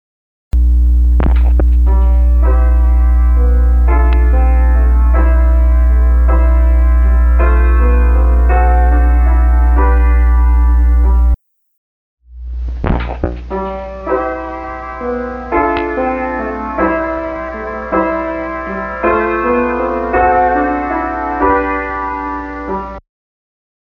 50 Year Old Analog Audio Noise Removal Issue.
/uploads/default/original/2X/f/f81f148abb39af31e68499dcf9d8dcffe4d579fc.flac Unfortunately the de-hum process does add artifacts and removes some music along with the mains hum harmonics.